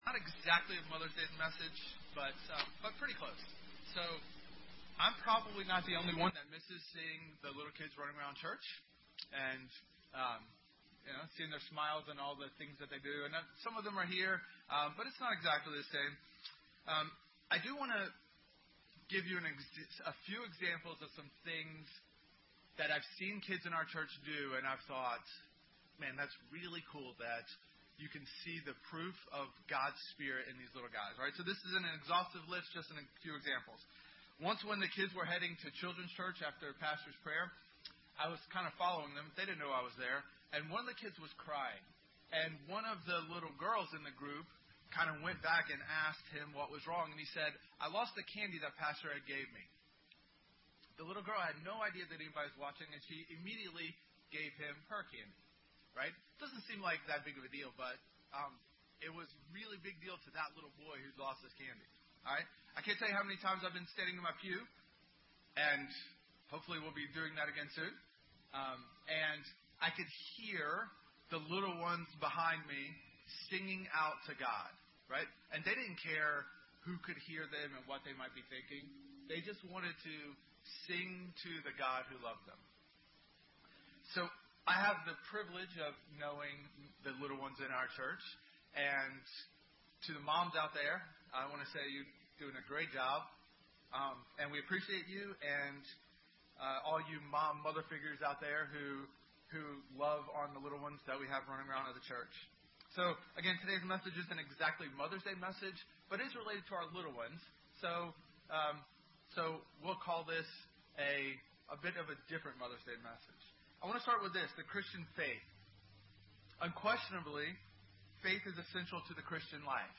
Mark 9:14-29 Service Type: Sunday Morning Posted by Faith Like a Child